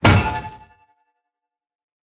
Minecraft Version Minecraft Version 25w18a Latest Release | Latest Snapshot 25w18a / assets / minecraft / sounds / item / mace / smash_air1.ogg Compare With Compare With Latest Release | Latest Snapshot
smash_air1.ogg